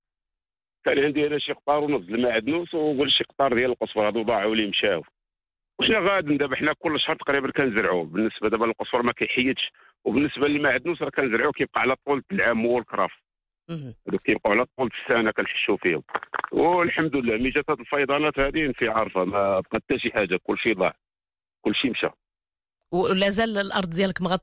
شهادة فلاح عن تأثير الفيضانات